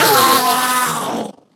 PlayMinecraft ender man die Sound Button